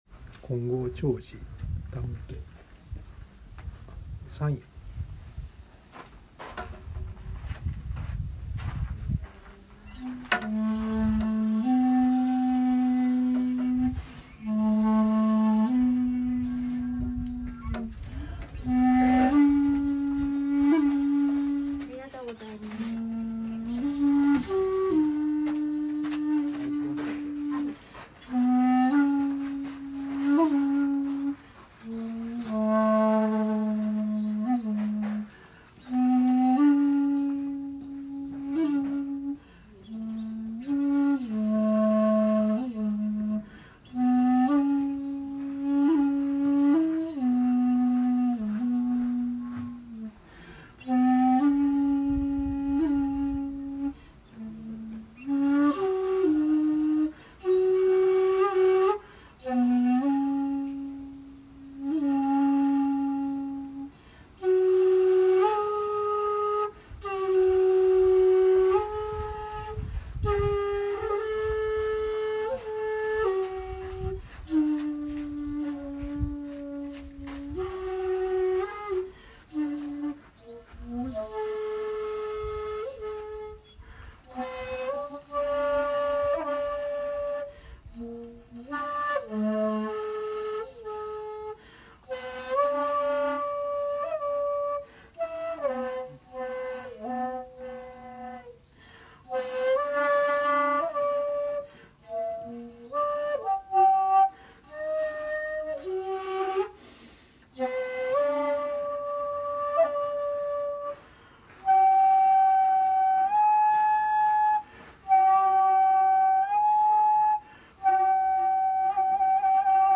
その後に尺八を吹奏しました。
◆◆　（尺八音源：金剛頂寺にて「山谷」）